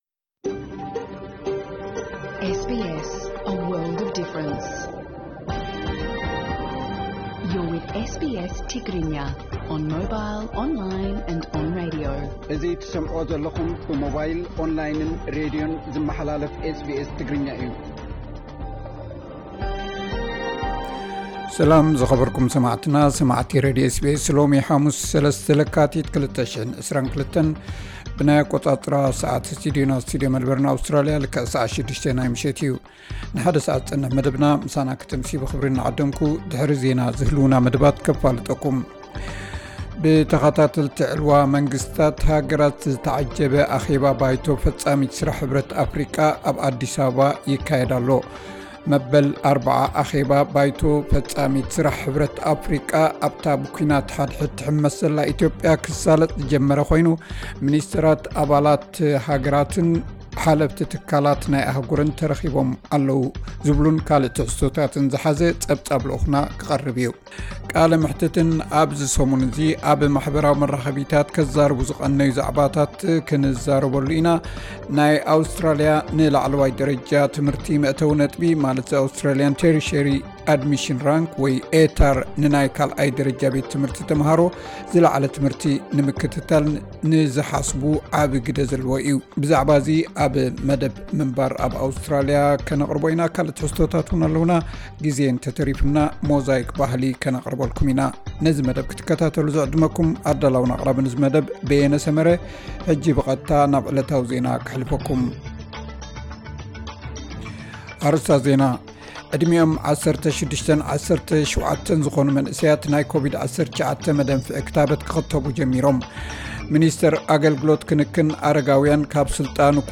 ዕለታዊ ዜና SBS ትግርኛ (03 ለካቲት 2022)